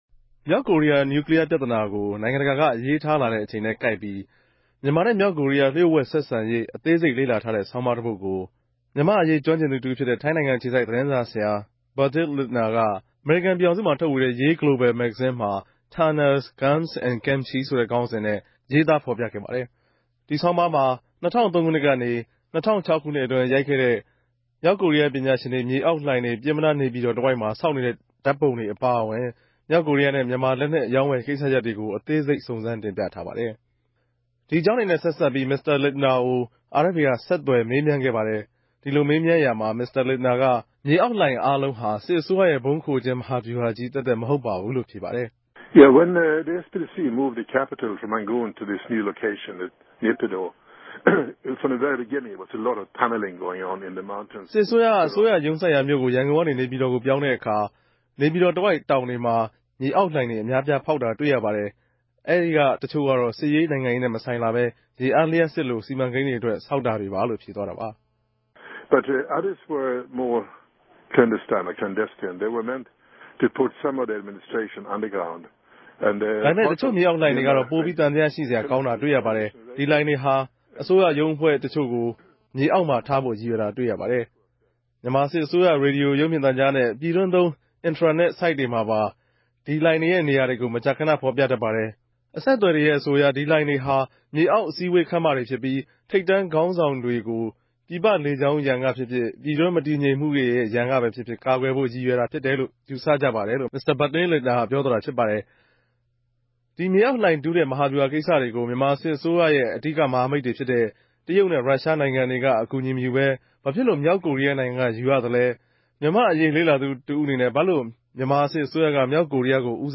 ဒီအေုကာင်းတေနြဲႛ ဆက်စပ်္ဘပီး မင်္စတာလင့်တနာကို RFAက ဆက်သြယ် မေးူမန်းခဲ့ပၝတယ်။ ဒီလိုမေးူမန်းရာမြာ မင်္စတာ လင့်တနာက ေူမအောက်လိုဏ်အားလုံးဟာ စစ်အစိုးရရဲ့ ဗုံးခိုကဵင်း မဟာဗဵဟြာခဵည်းသက်သက် မဟုတ်ပၝဘူးလိုႛ ေူဖပၝတယ်။